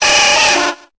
Cri de Carapuce dans Pokémon Épée et Bouclier.